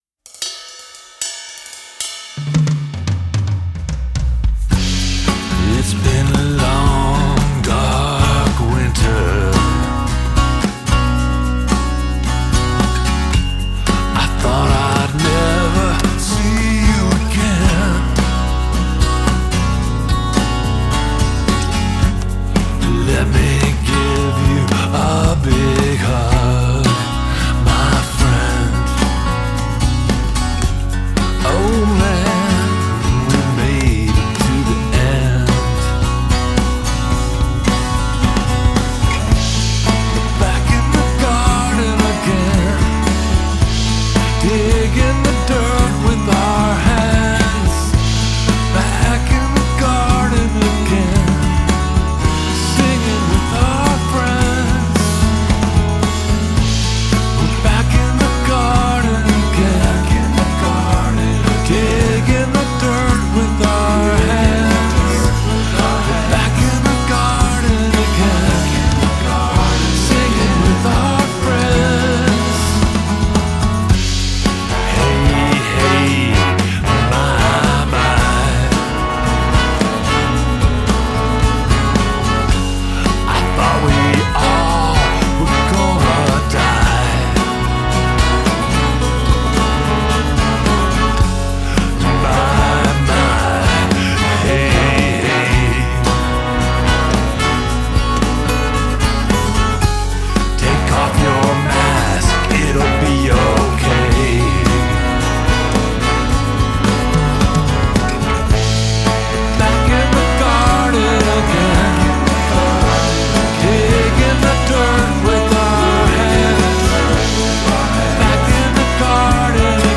6- and 12-string acoustic guitars
trumpet
trombone
tenor saxophone
baritone saxophone
wooden flute